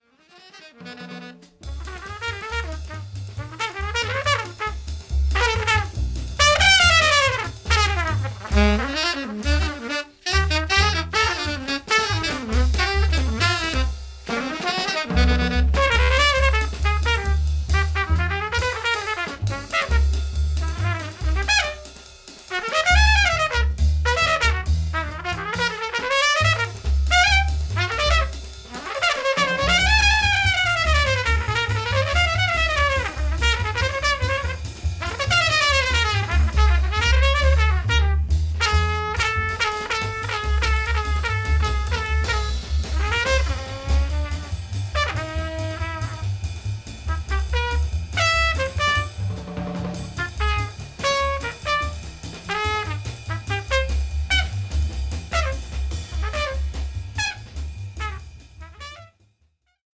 ソプラノ・サックスをプレイした初の作品でもあります。